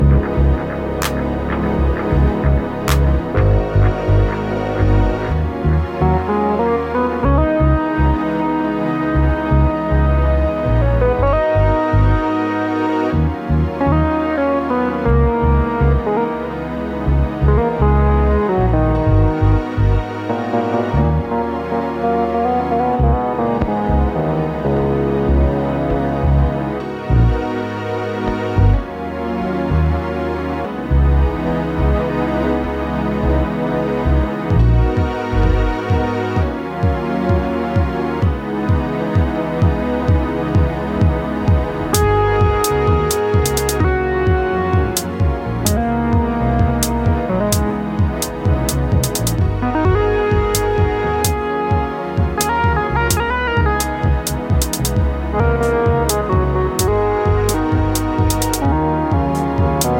Electronix Jazz Ambient